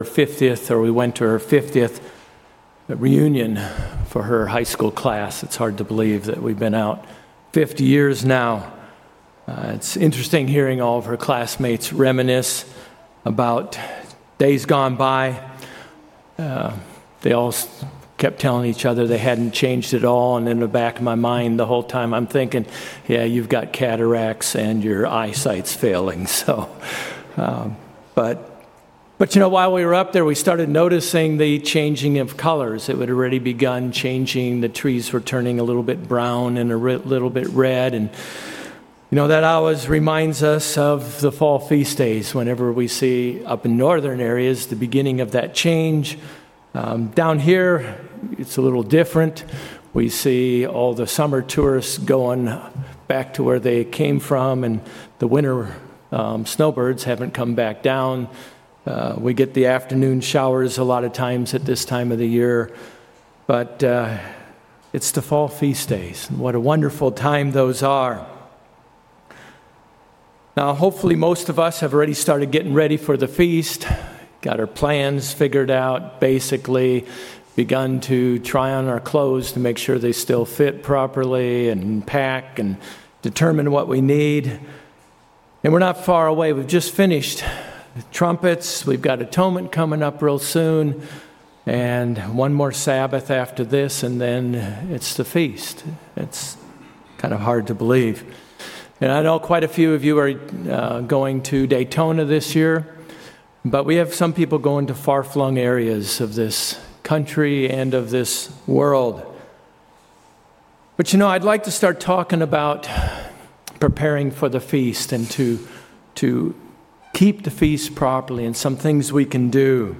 It is good to review the reasons why we keep the Feast. The sermon also presents four "dares" concerning the Feast to help us have a more profitable Feast.